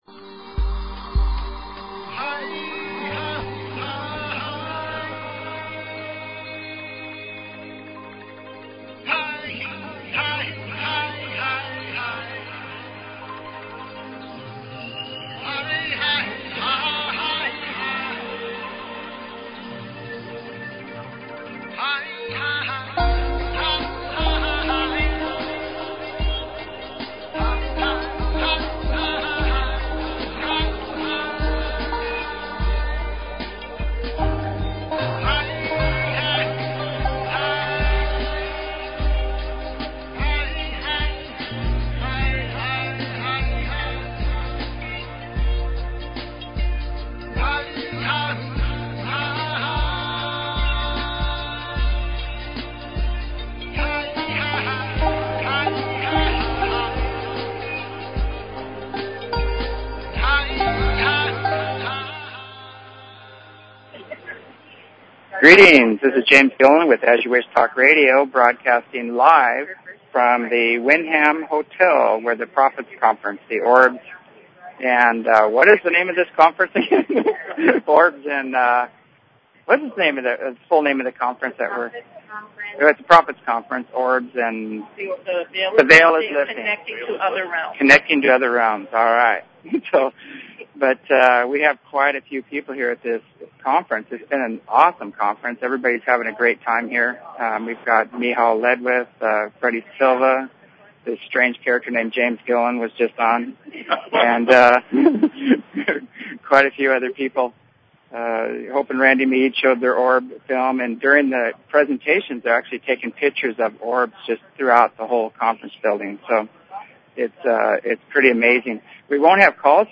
Talk Show Episode, Audio Podcast, As_You_Wish_Talk_Radio and Courtesy of BBS Radio on , show guests , about , categorized as
LIVE BROADCAST FROM THE PROPHETS CONFERENCE PALM SPRINGS